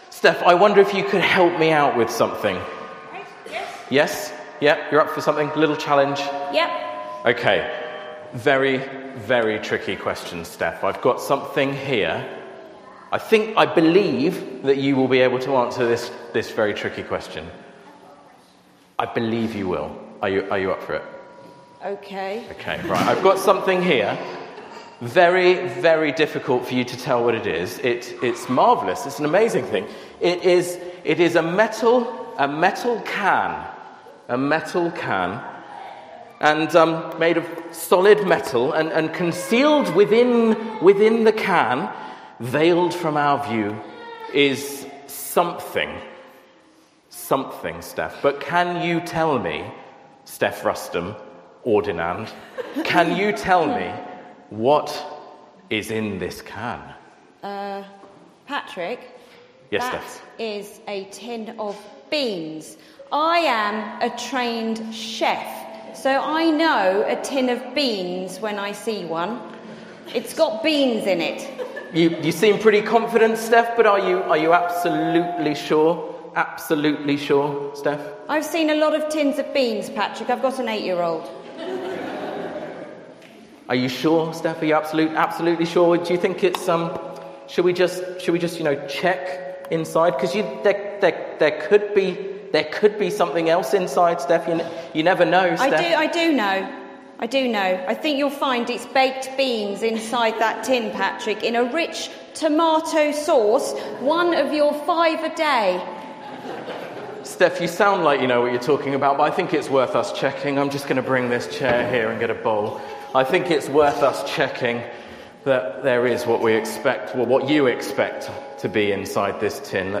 Service Type: St Andrew's at 10 - All Age